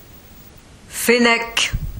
Ääntäminen
Synonyymit renard des sables renard des sables du Sahara renard du Sahara Ääntäminen France (Paris): IPA: /fe.nɛk/ Haettu sana löytyi näillä lähdekielillä: ranska Käännös Substantiivit 1. fénec {m} Suku: m .